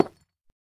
Minecraft Version Minecraft Version snapshot Latest Release | Latest Snapshot snapshot / assets / minecraft / sounds / block / deepslate / place4.ogg Compare With Compare With Latest Release | Latest Snapshot